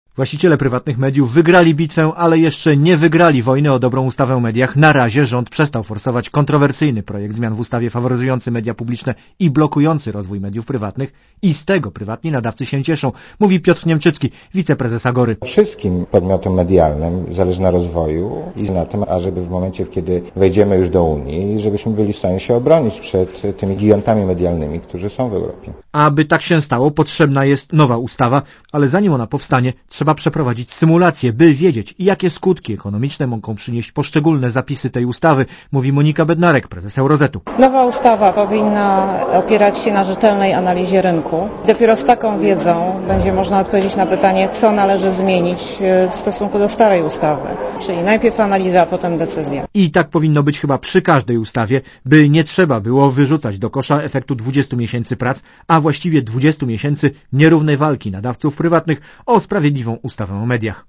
Relacja reporetera Radia Zet (240Kb)